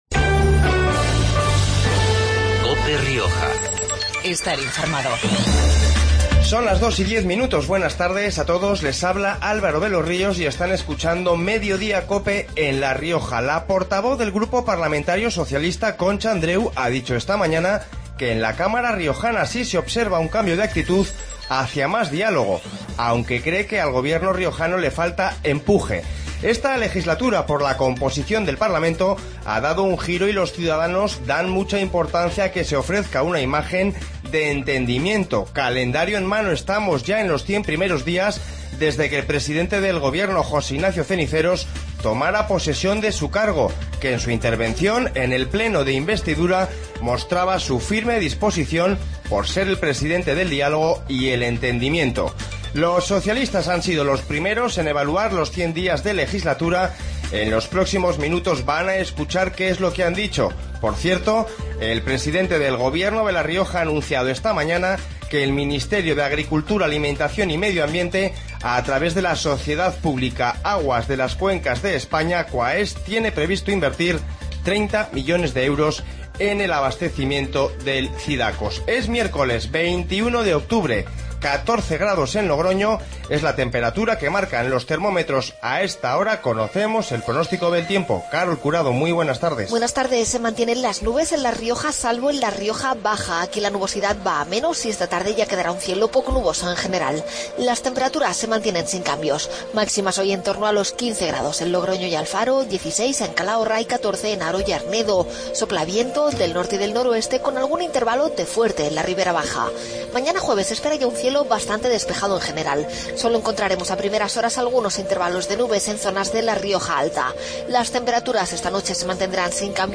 Informativo Mediodia en La Rioja 21-10-15